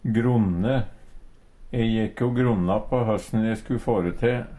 gronne - Numedalsmål (en-US)